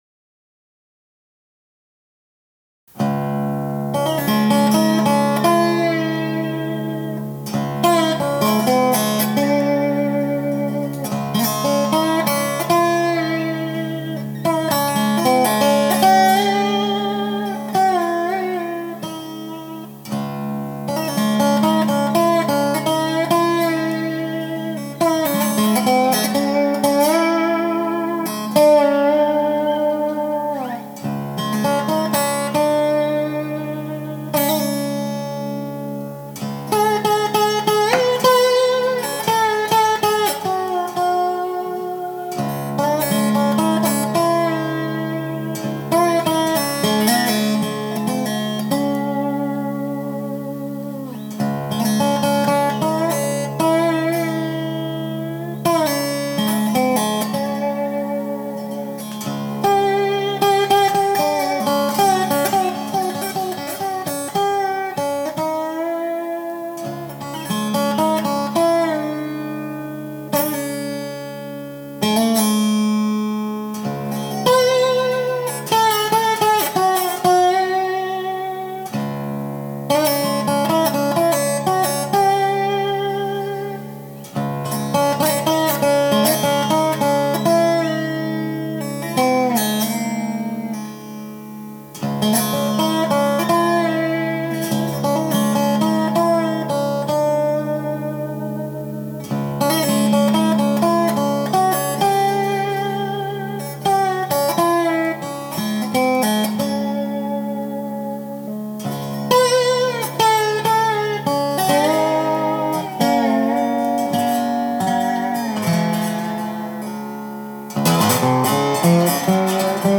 an improvised slide guitar piece